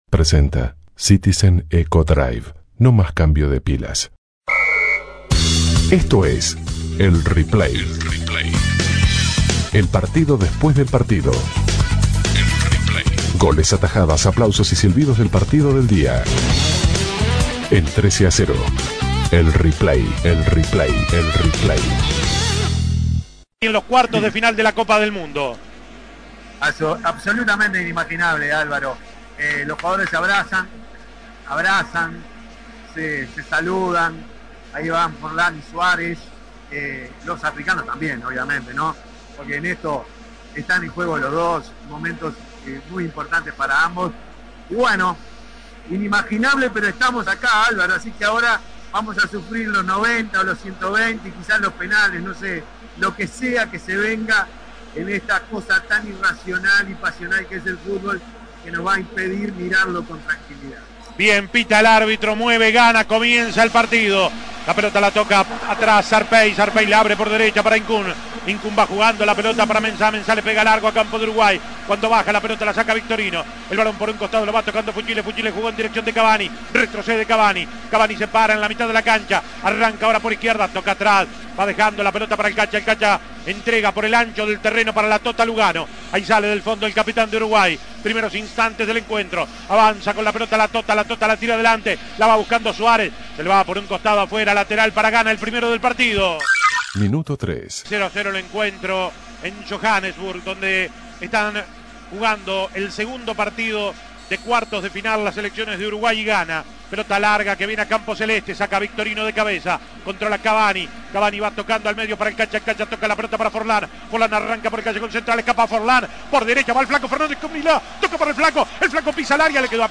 Goles y comentarios Escuche El Replay de la victoria de Uruguay ante Ghana Imprimir A- A A+ Uruguay derrotó por penales a Ghana y clasificó a la semifinal del Mundial.